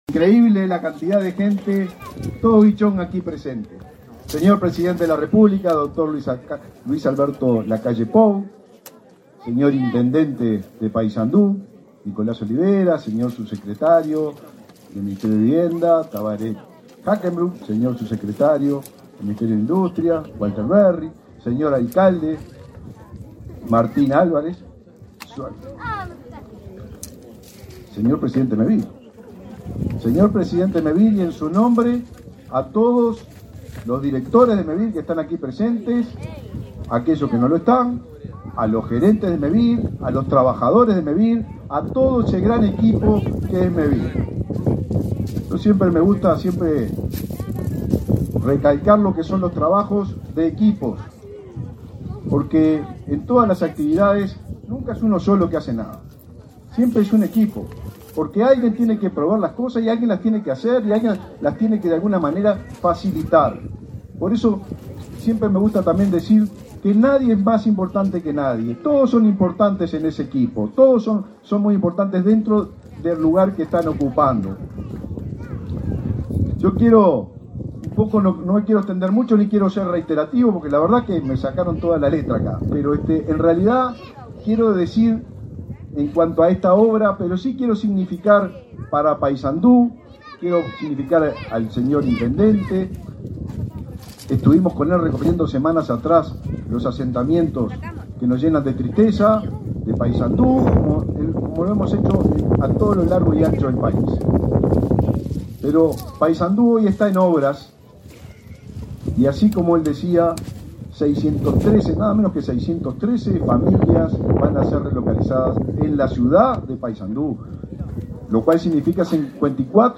Palabras del ministro del MVOT, Raúl Lozano
Palabras del ministro del MVOT, Raúl Lozano 14/08/2024 Compartir Facebook X Copiar enlace WhatsApp LinkedIn Con la presencia del presidente de la Repúbica, Luis Lacalle Pou, fueron inauguradas, este 14 de agosto, 61 viviendas en la localidad de Guichón, que beneficiarán a unas 200 personas. En el evento, el ministro de Vivienda y Ordenamiento Territorial (MVOT), Raúl Lozano, realizó declaraciones.